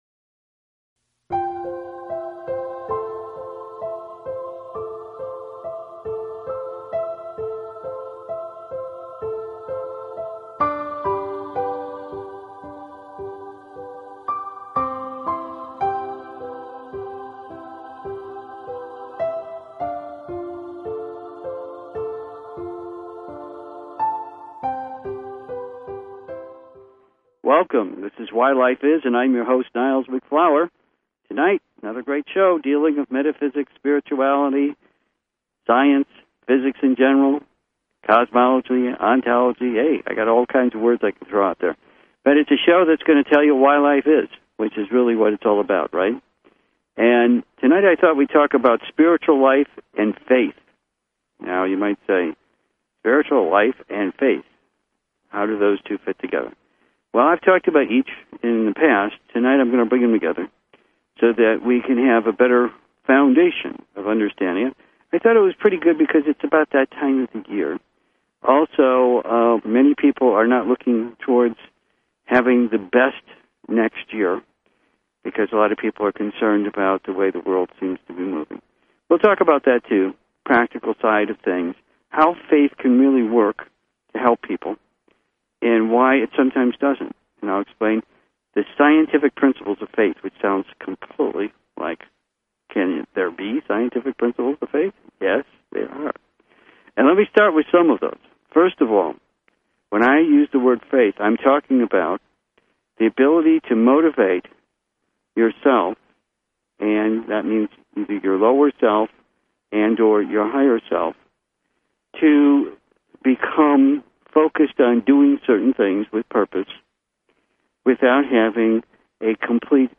Subscribe Talk Show Why Life Is...